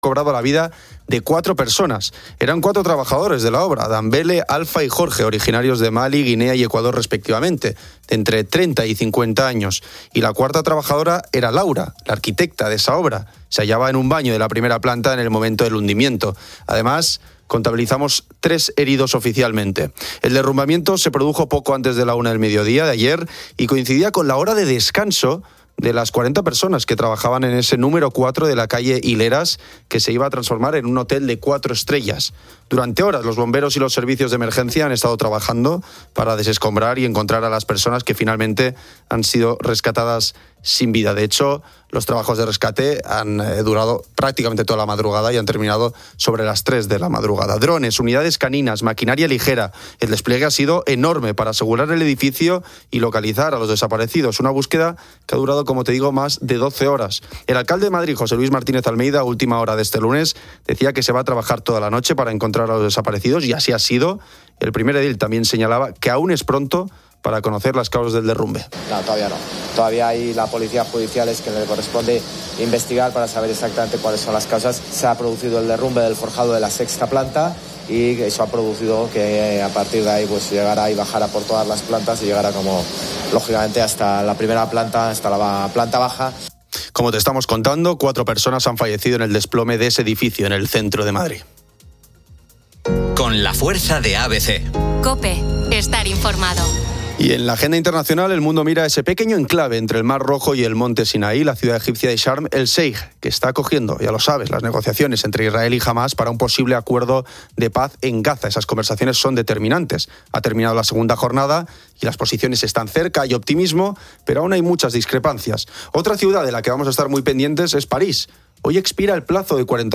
Los oyentes comparten experiencias sobre los grupos de WhatsApp.